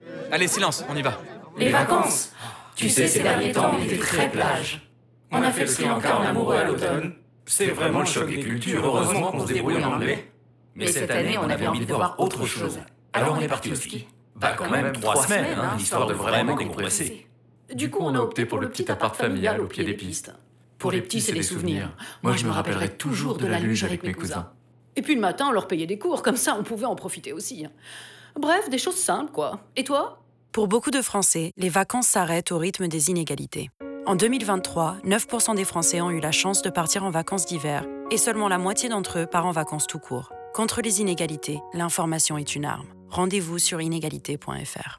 Grâce à l’écriture de trois spots radio d’un genre totalement inédit : chacun raconte une histoire portée par les voix de 10 comédiens représentatifs de la société française.
Le travail du son, orchestré par le studio O’Bahamas, a fait l’objet d’une attention toute particulière pour faire ressortir les caractéristiques de chacune des voix même lors des choeurs.
Spot radio 3 :